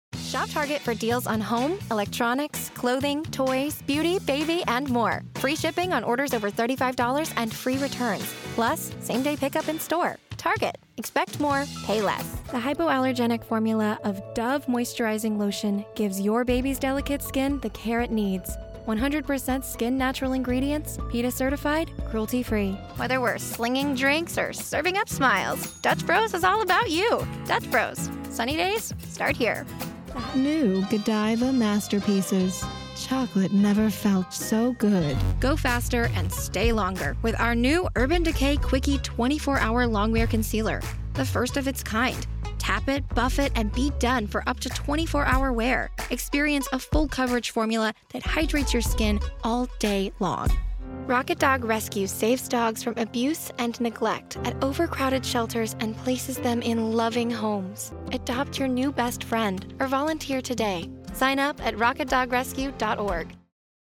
Inglés (Americano)
Joven, Travieso, Versátil, Amable, Cálida
Comercial